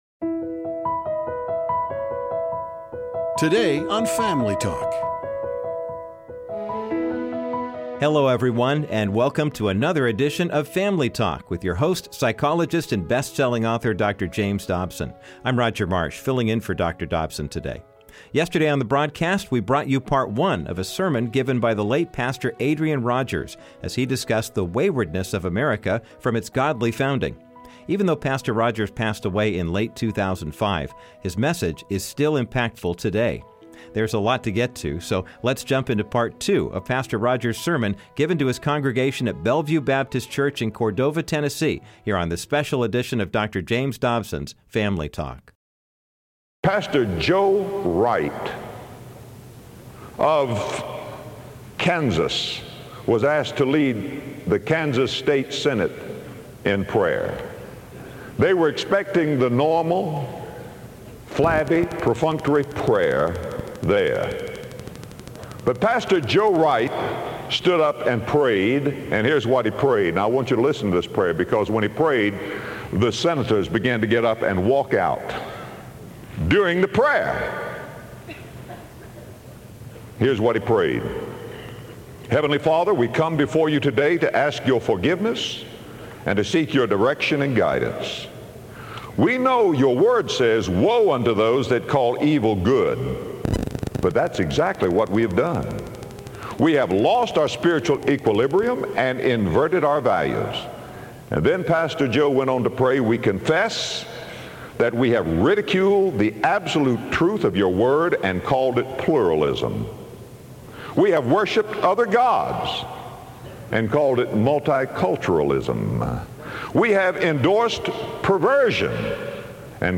Culture in America today has pushed God out of the public square and become more tolerant of wickedness. the late Pastor Adrian Rogers continues his sermon through Psalm 80 and explains why America must stop its sinful waywardness and return to God.